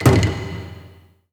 A#3 DRUMS0EL.wav